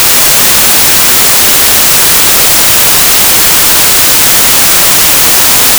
今回は、入力に44.1kHzでサンプリングされたホワイトノイズを使用。
ホワイトノイズには、全ての周波数が同じレベルで入っています。
(音がデカイので注意)
入力信号(ホワイトノイズ)